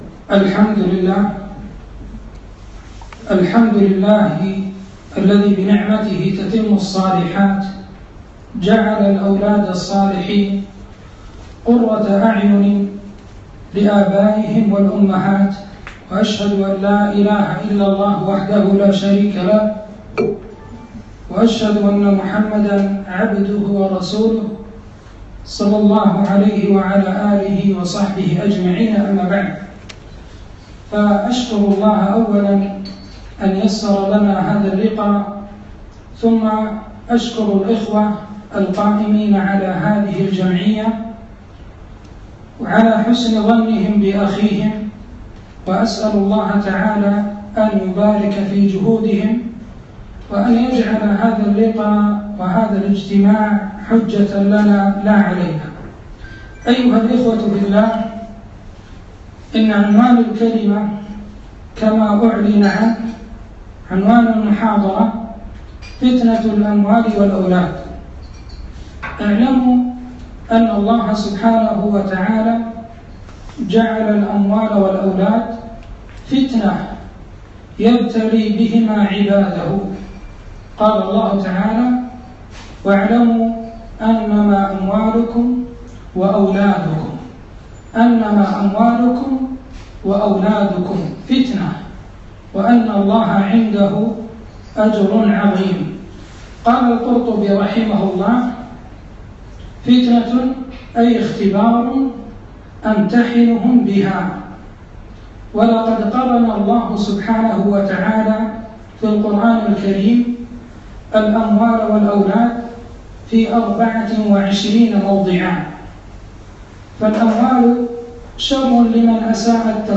محاضرة - فتنة الأموال والأولاد